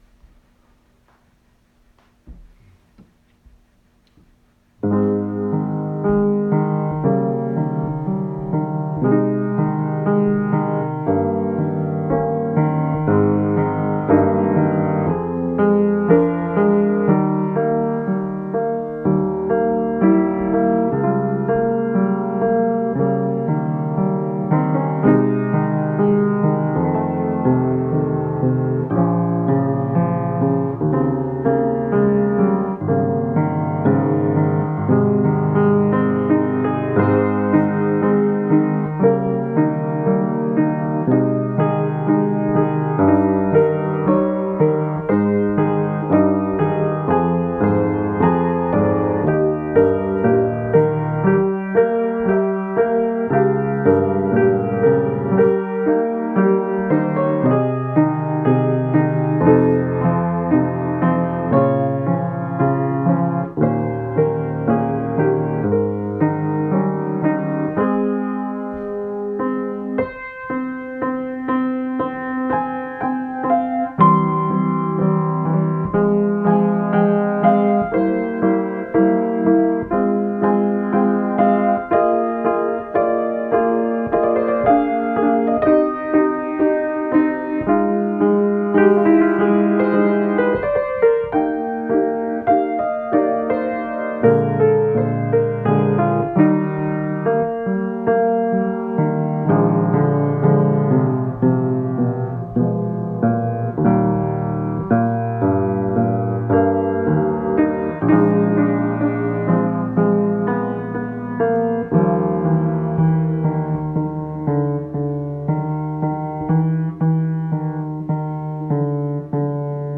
Links to recorded Easter music can be found below.
piano.